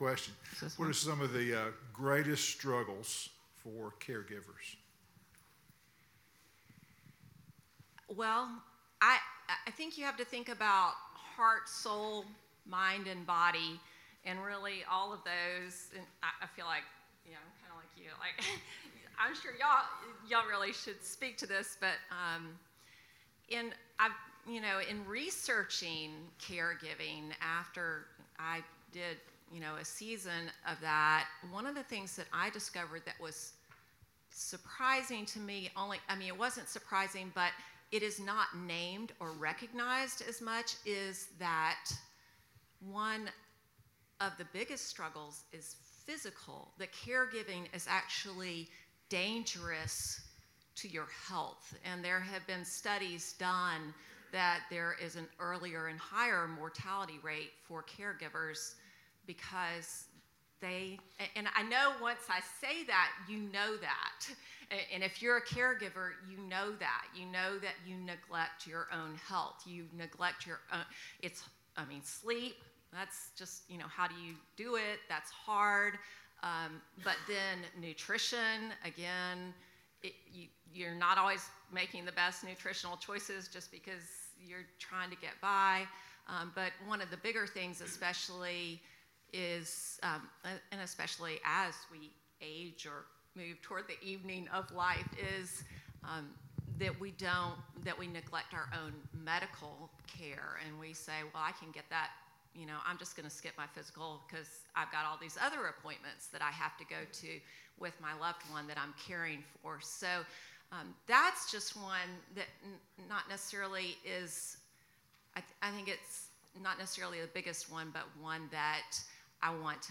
Evening-of-Life-QA-Session-1.mp3